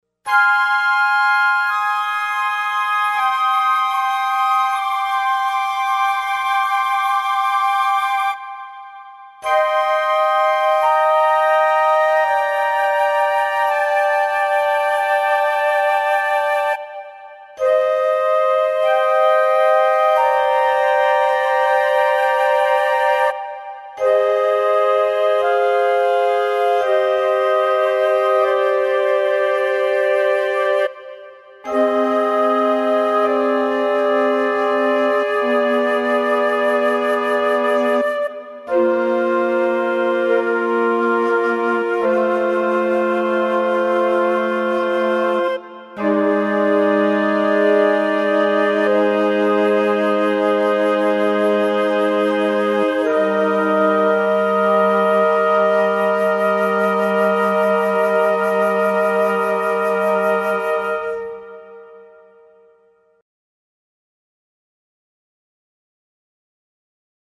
The lower octave and a half is English horn and alto flute, the remainder being oboe with C flute. The split point between horn/oboe is offset from the split point between Alto/c flute to give a smoother transition.
Very pretty indeed.